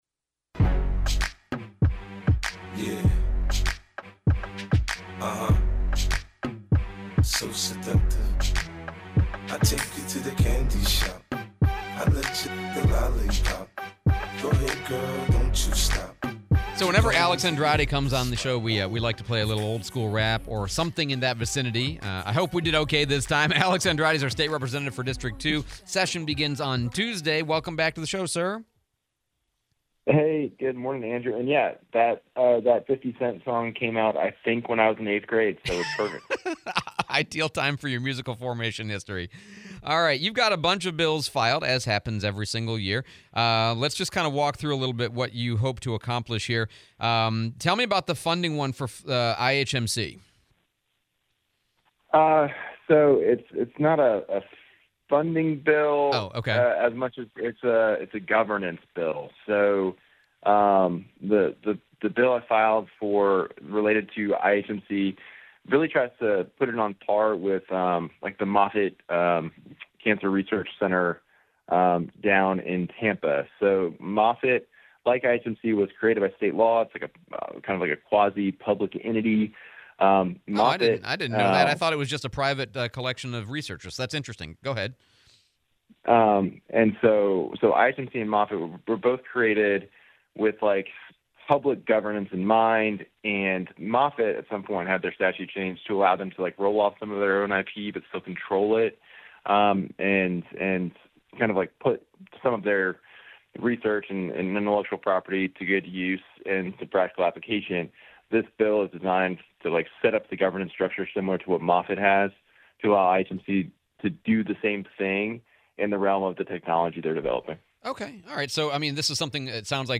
02/27/25 Interview with Rep Andrade